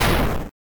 bang2.ogg